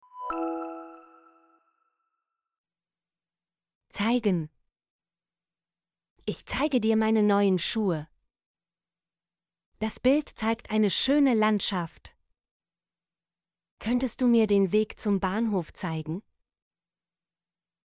zeigen - Example Sentences & Pronunciation, German Frequency List